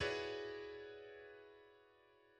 A thirteenth chord (E 13, which also contains a flat 7th and a 9th)
Thirteenth_chord_collapsed.mid.mp3